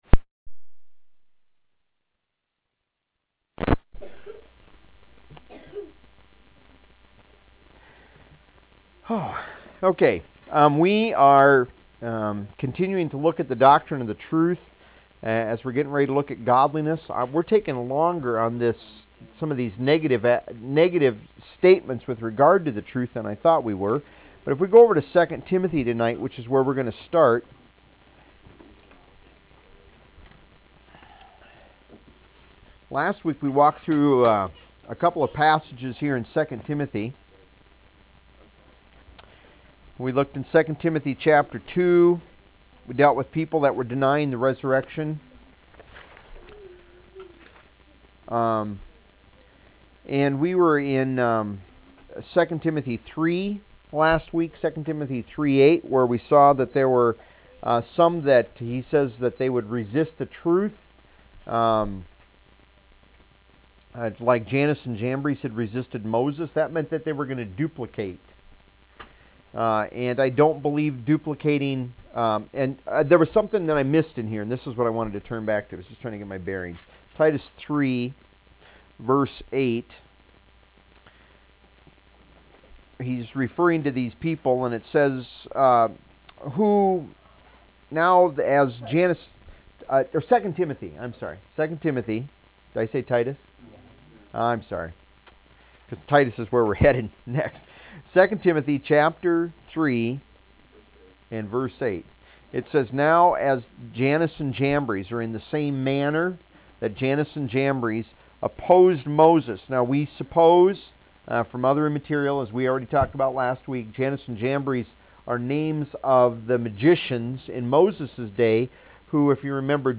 PLEASE NOTE: When you are listening to these audio files, keep in mind that you might hear the sound of children or of people asking questions during the teaching.
You will not find a strict, formal, religious ceremony atmosphere in these Bible teachings. Also note that due to technical difficulties, some earlier recordings might be more difficult to hear.